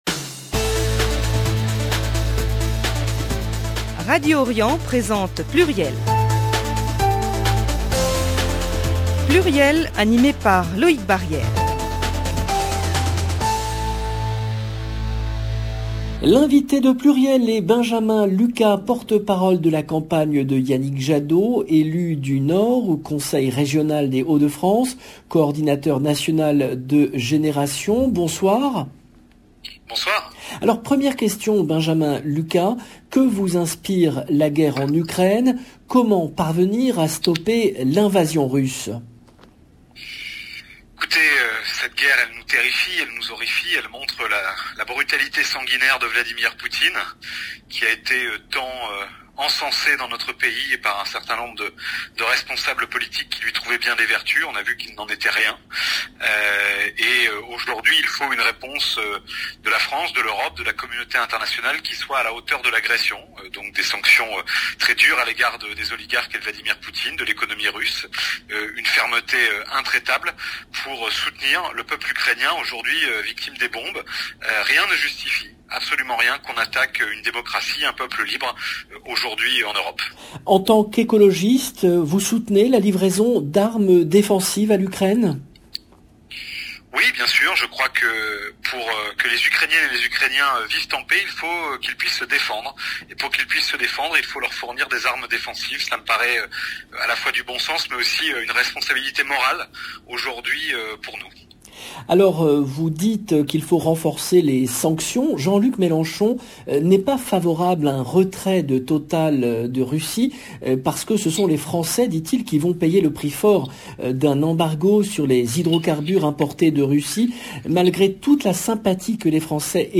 L’invité de PLURIEL est Benjamin Lucas , porte-parole de la campagne de Yannick Jadot | Élu du Nord au Conseil régional des Hauts-de-France